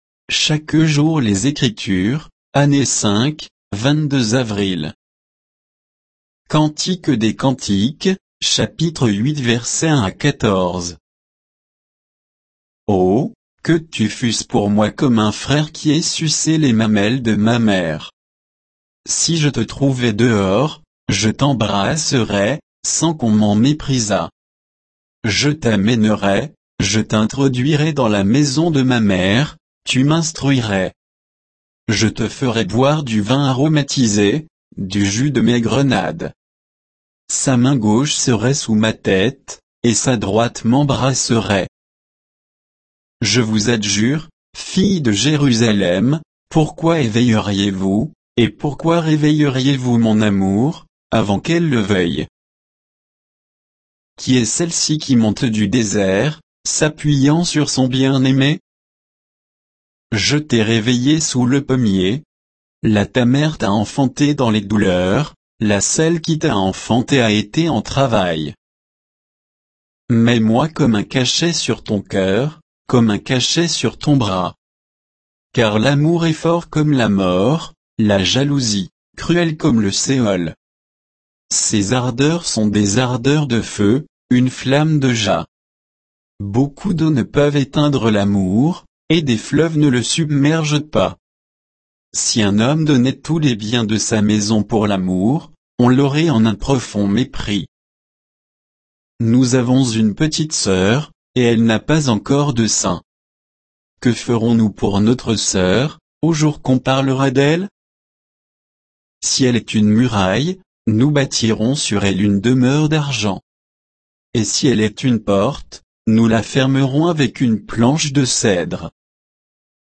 Méditation quoditienne de Chaque jour les Écritures sur Cantique des cantiques 8, 1 à 14